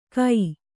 ♪ kai